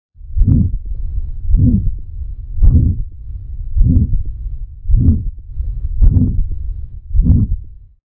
相對的，心跳雜音是指正常心音外還多出了其他聲音，例如，心臟組織若是發生病變，血液流動受阻，像是血液摩擦鈣化變硬的瓣膜，或是撞擊形狀改變的心臟腔室壁和血管壁，便會出現異樣的聲響，像是「轟~轟~」聲或「呼~呼~」聲。